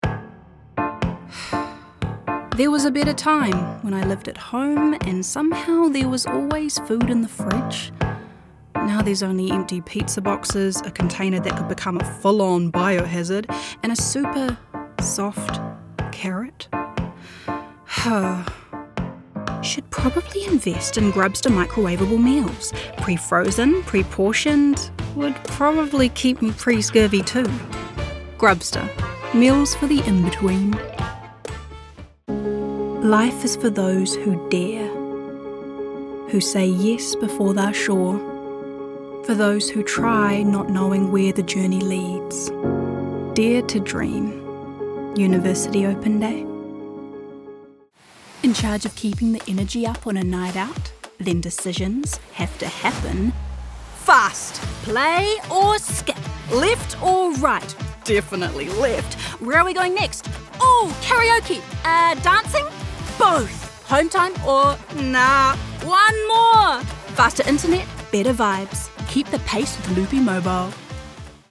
Teenager, Young Adult, Adult
new zealand | natural
COMMERCIAL 💸
NARRATION 😎
warm/friendly
youthful